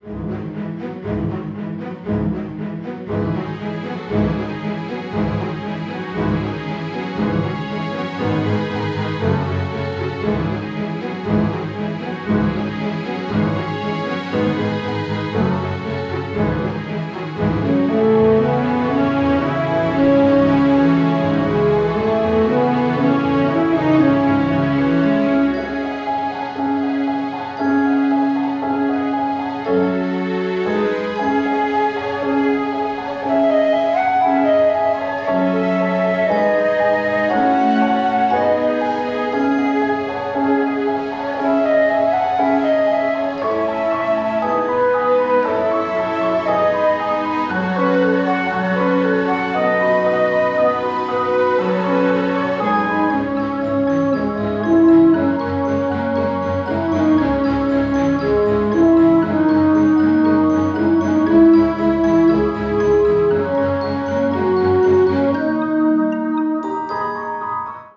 original motion picture soundtrack